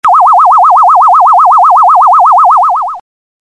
Звуки НЛО [14кб]